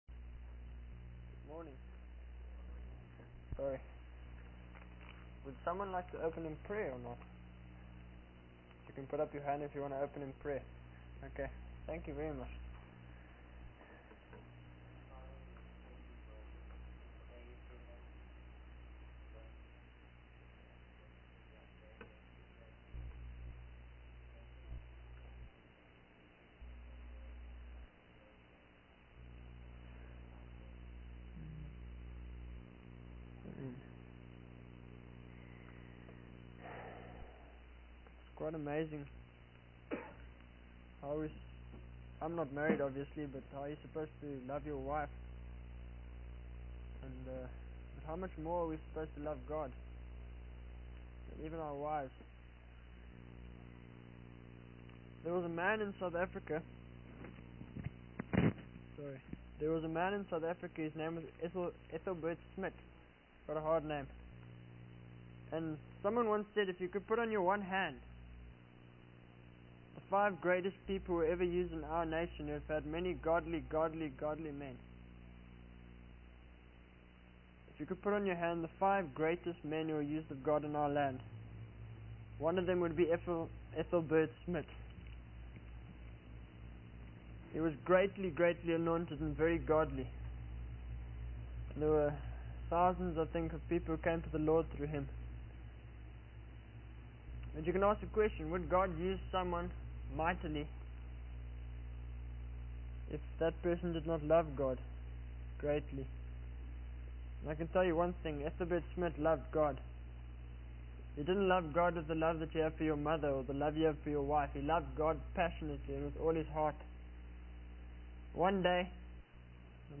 In this sermon, the preacher emphasizes the importance of not being deceived by false teachings. He speaks about the joy that comes from knowing God and how it surpasses the joy found in the world.